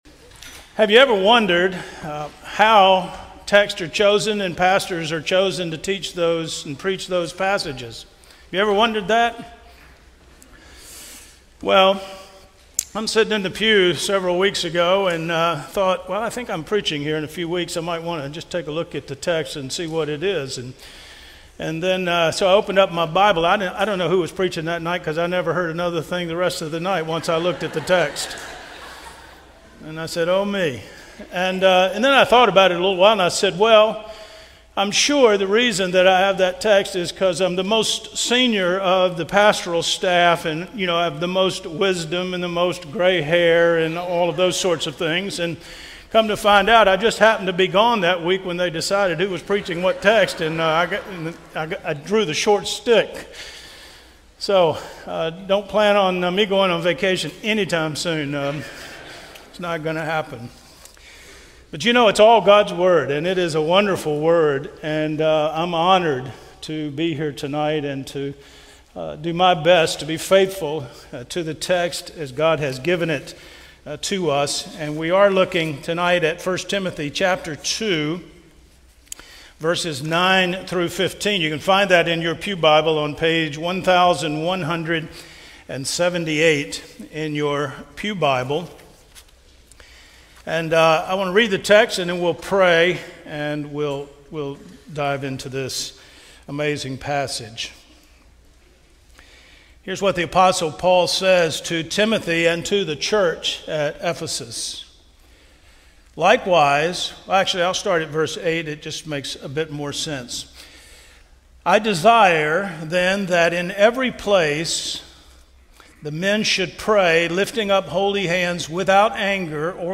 A sermon from the series "Passing on the Faith."